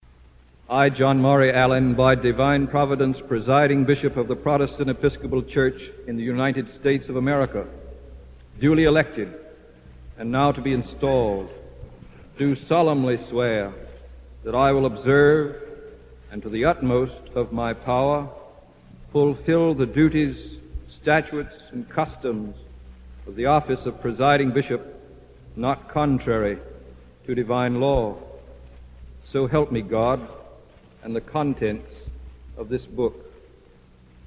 Allin Presiding Bishop Installation Oath